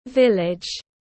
Làng quê tiếng anh gọi là village, phiên âm tiếng anh đọc là /ˈvɪl.ɪdʒ/.